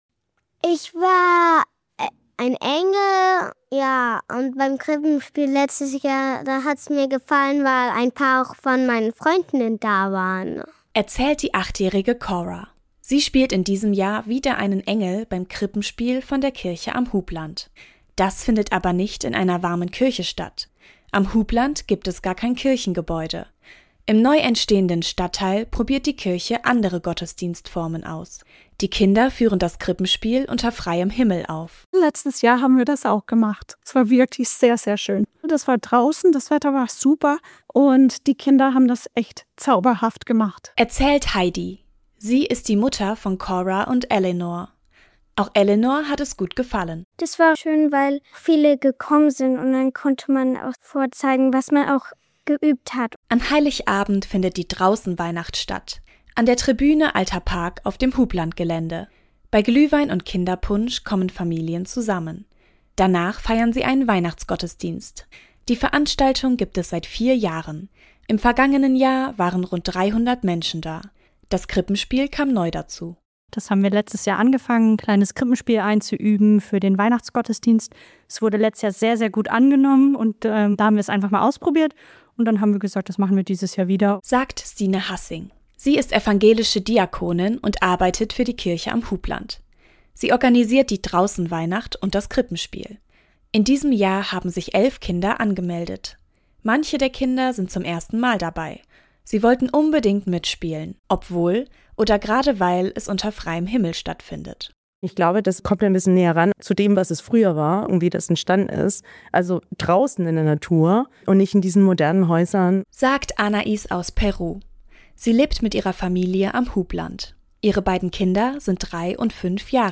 Geschichten und Berichte aus dem Bistum Würzburg